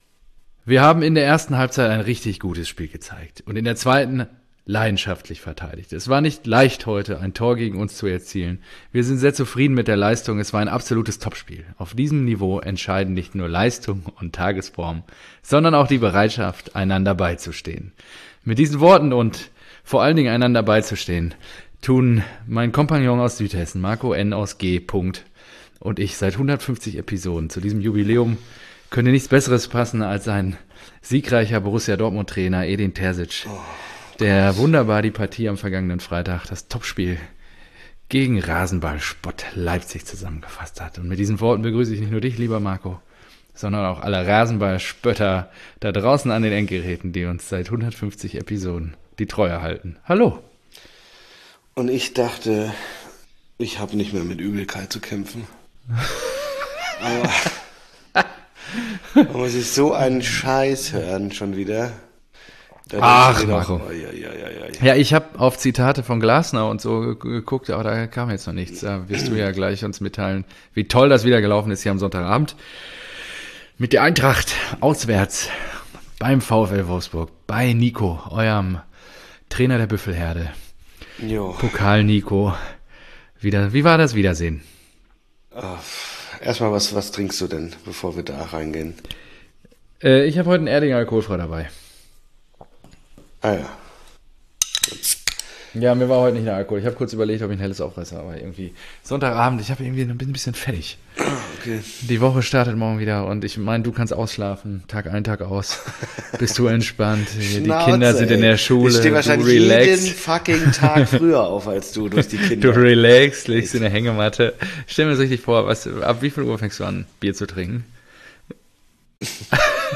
Und dann kommen wir daher und machen eine ruhige und gechillte Jubiläumsfolge.